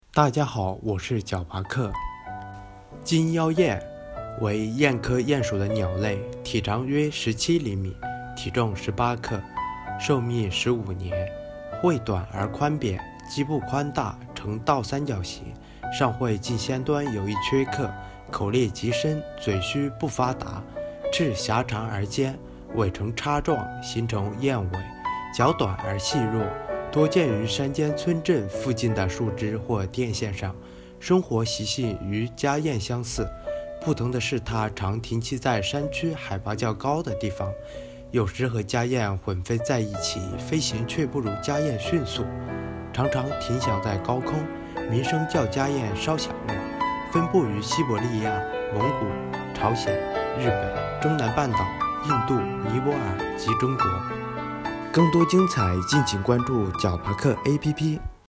金腰燕-----呼呼呼～～～
有时和家燕混飞在一起，飞行却不如家燕迅速，常常停翔在高空，鸣声较家燕稍响亮。